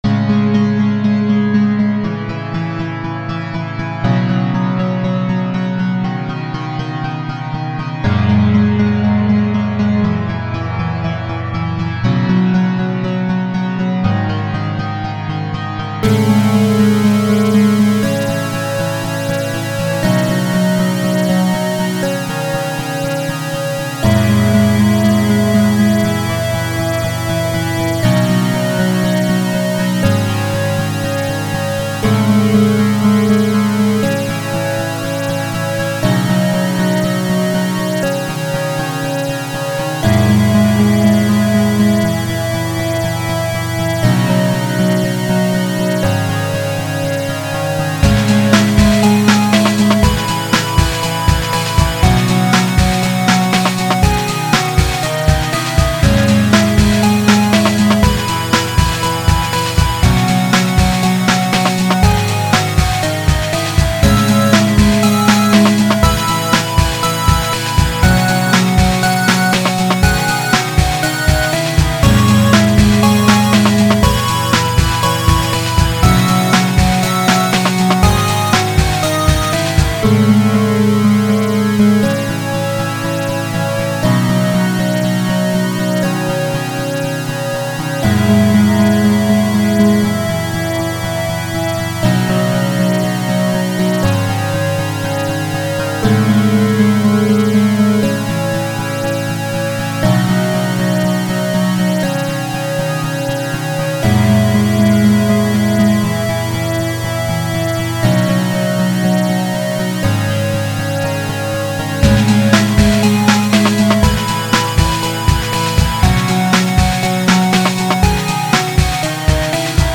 electronic voxchop sample